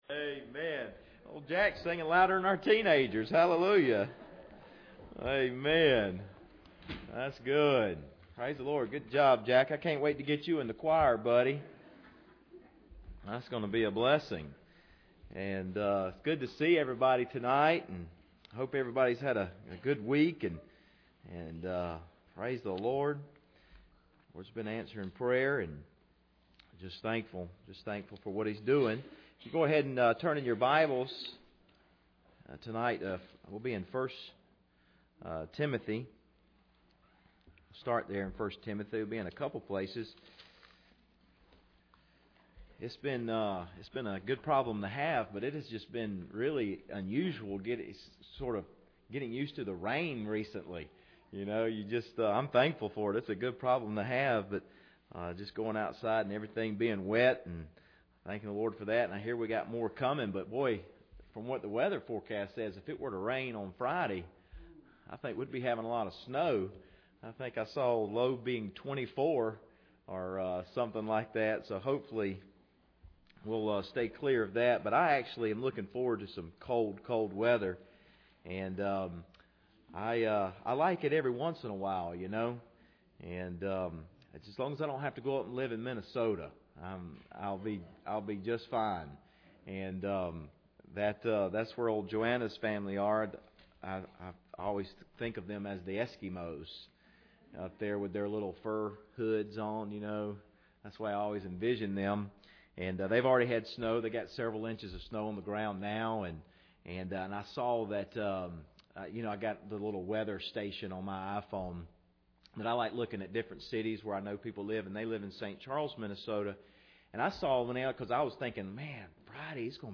Passage: 1 Timothy 2:1-4 Service Type: Wednesday Evening